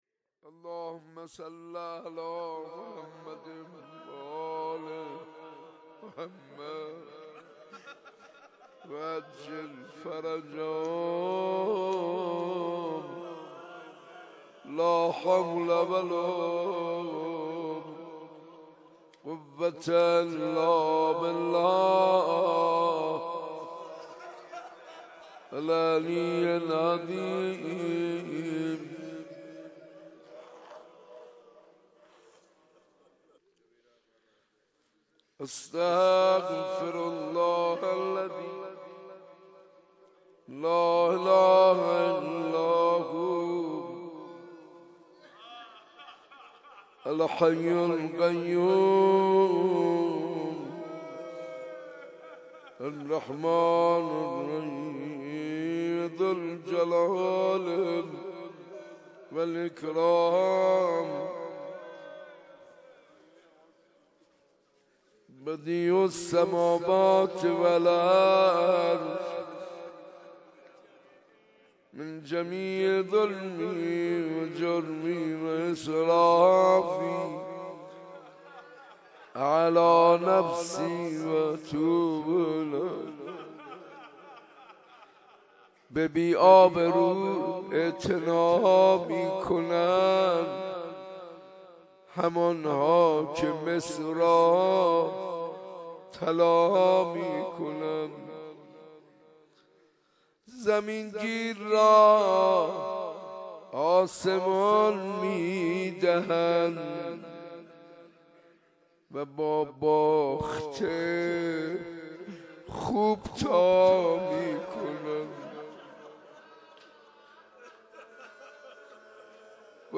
دعای کمیل 8-8-1394 | حرم حضرت عبدالعظیم حسنی
به بی آبرو اعتنا می کنند | مناجات با خدا